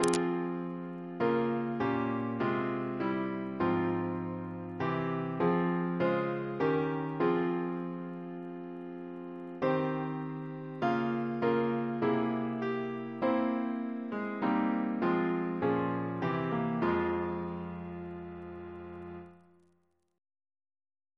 Double chant in F minor Composer: Edwin Edwards (1830-1907)